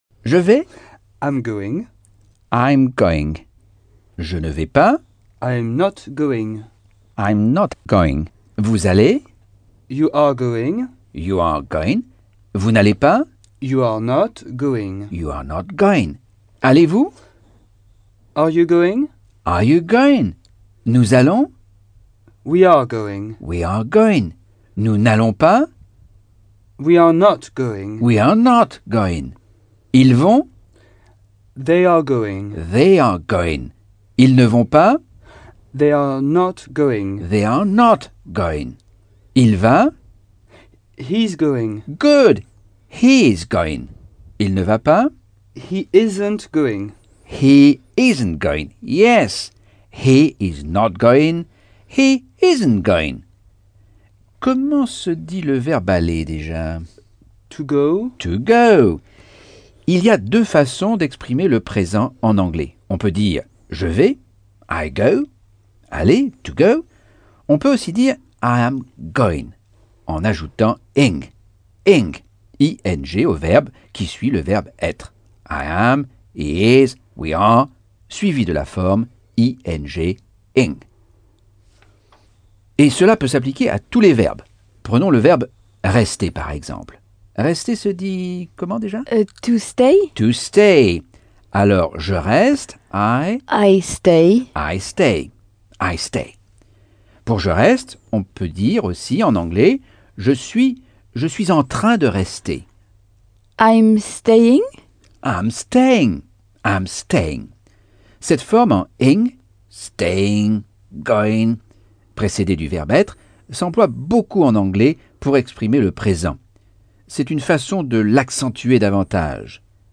Leçon 5 - Cours audio Anglais par Michel Thomas